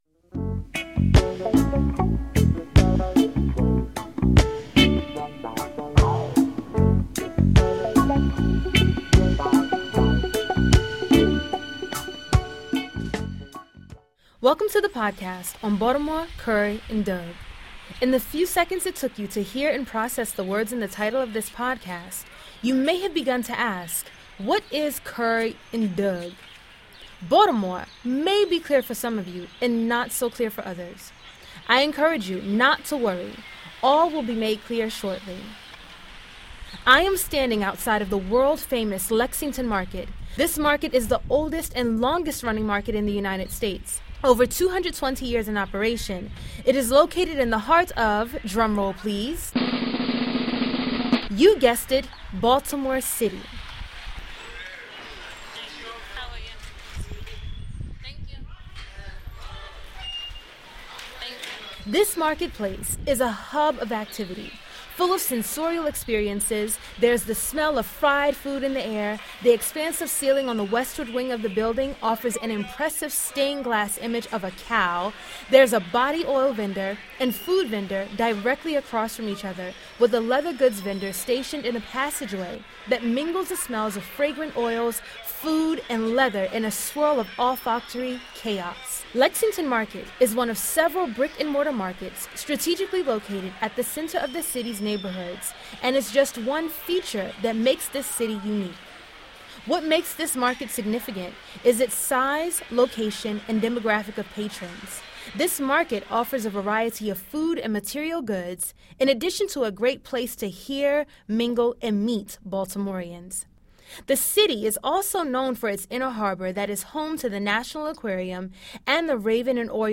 There are many accents within the urban landscape of Baltimore, with Appalachian, African American, Chesapeake, and Eastern Shore influences, among others. In this podcast, I investigate accents among African Americans in Baltimore.
In this podcast, I explore three main linguistic features in three parts: (1) The pronunciation of the name Baltimore as “Baldamor,” (2) The pronunciation of the vowel sound “urr” in which words like carry are pronounced as curry , and (3) The pronunciation of dog as “dug.” I interview a variety of Baltimore natives, including several members of a family that has lived in the city for four generations, as well as Baltimore transplants, some of whom have begun to adopt the local pronunciations they have heard here.